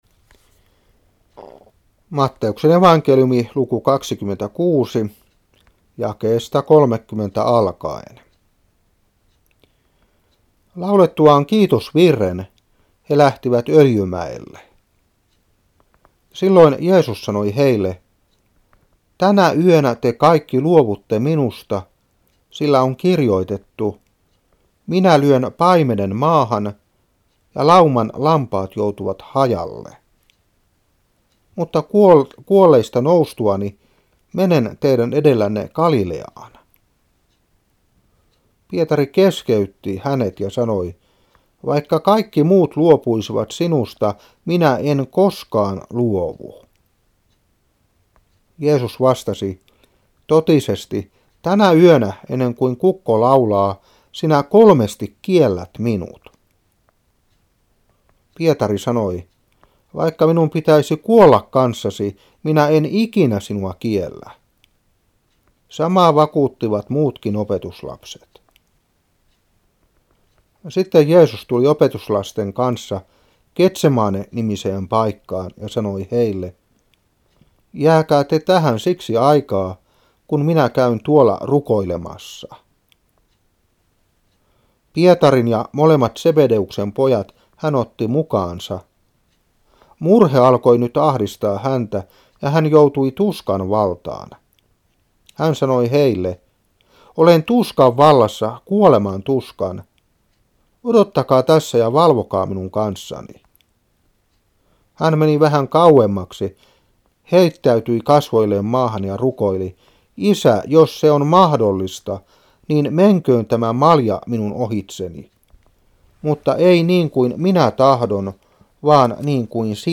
Saarna 1992-3.